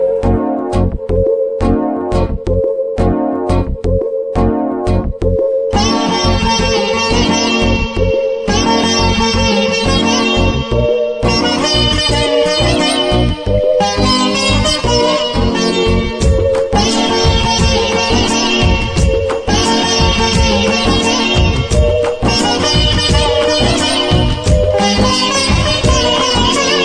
tamil ringtonelove ringtonemelody ringtoneromantic ringtone
best flute ringtone download